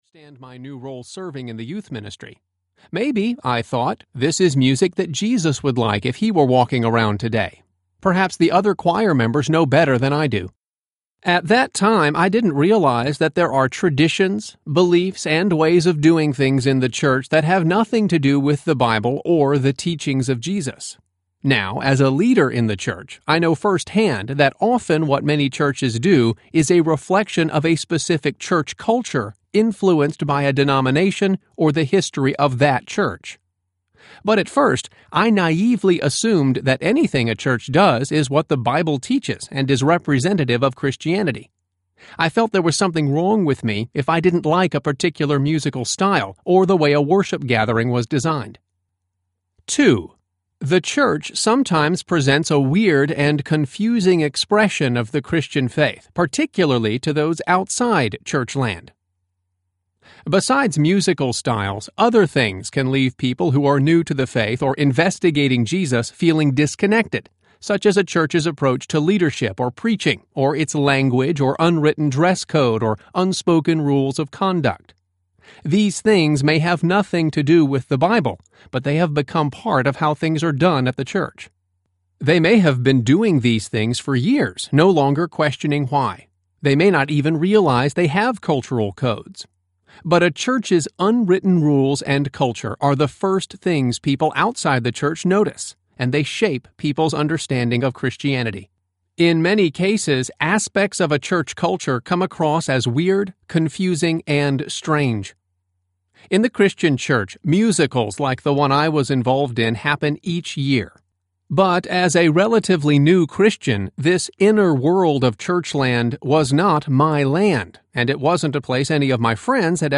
Adventures in Churchland Audiobook
Narrator
6.8 hrs. – Unabridged